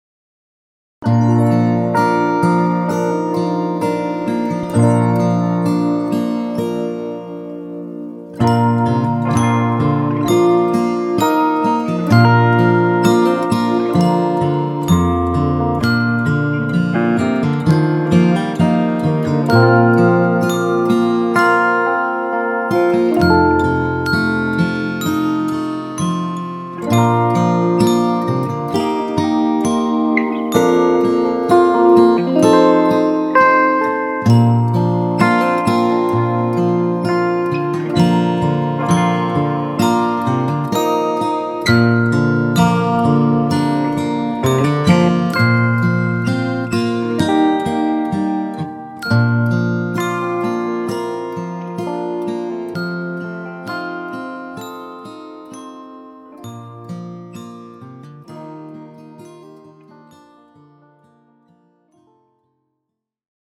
Latviešu tautas dziesma Play-along.